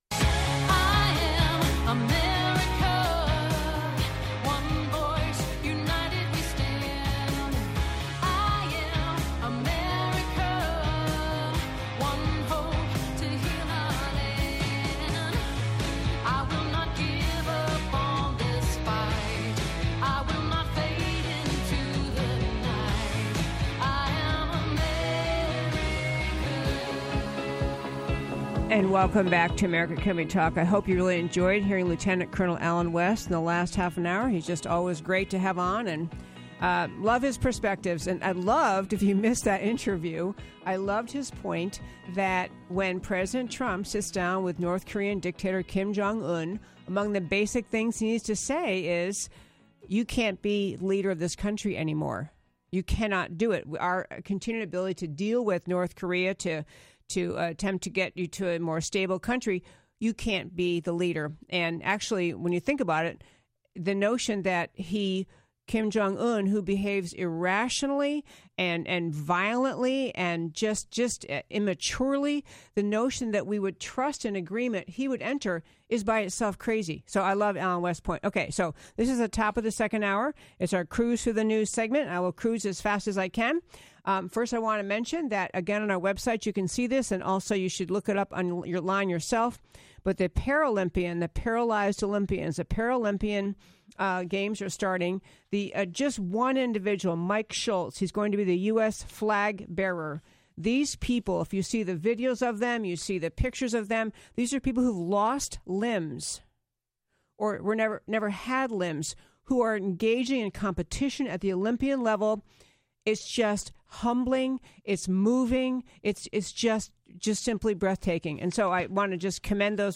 11 Mar HR 2 – Cruise thru the News Posted at 08:15h in Radio Show Podcasts by admin HR 2 – Cruise thru the News; Feminity and Masculinity are Good Things; South African Regression; Farrakhan?